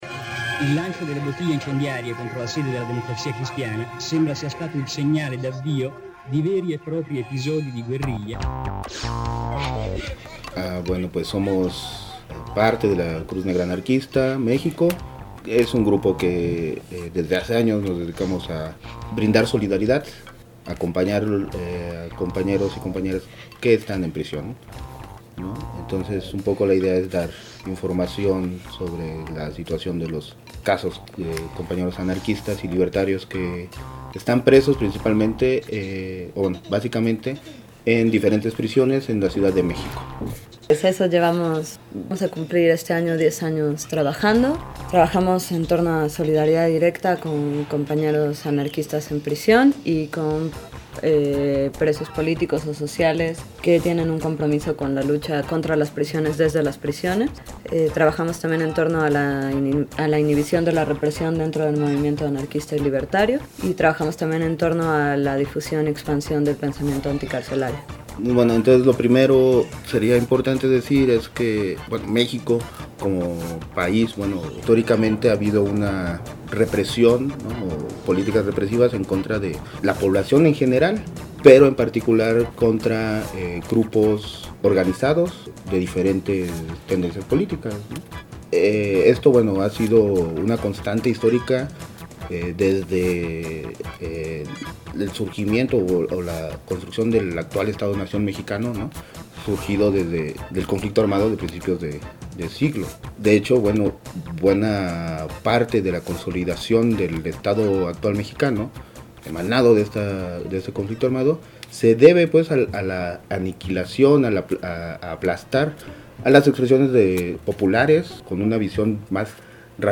Entrevista a La Cruz Negra Anarquista México. Parte 1
Esta es la primera de tres partes de una entrevista con dos compas de la Cruz Negra Anarquista México, acerca de la represión que se está viviendo